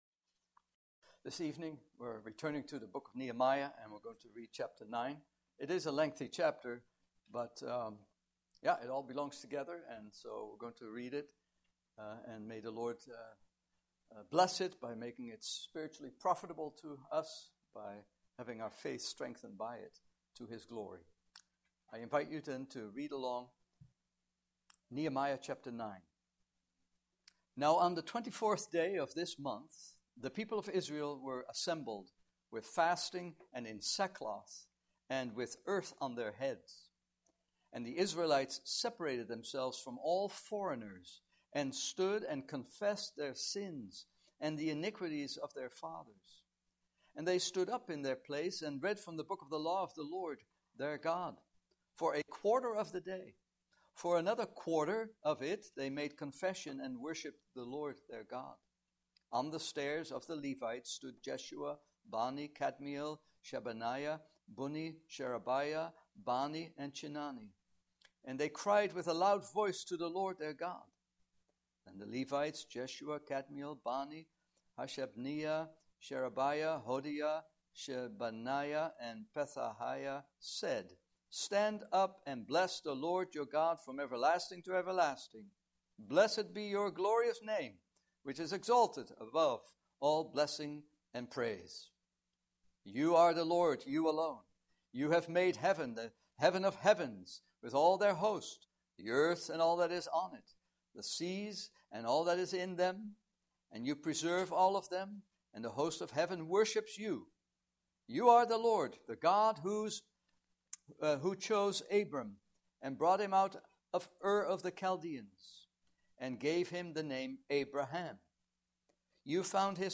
Passage: Nehemiah 9 Service Type: Evening Service « Christ